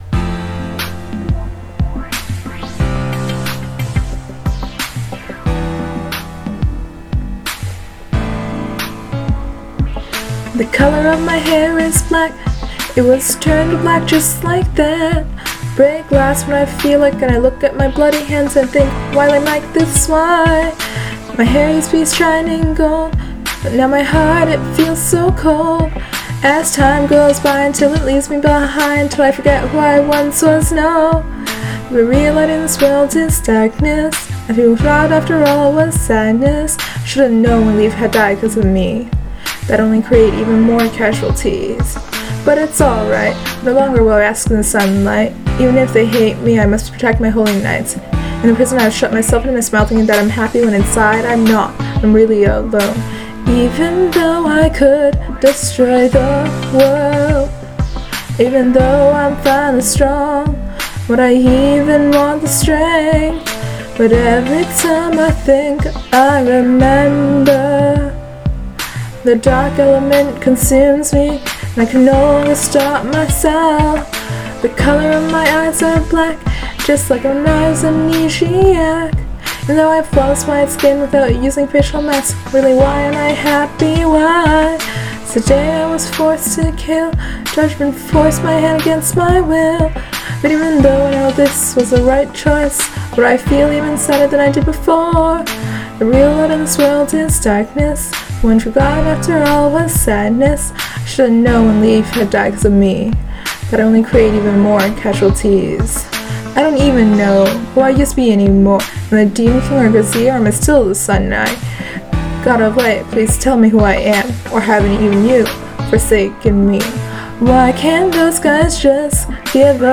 We finally finished singing covers for those songs!
Staff Rendition 2: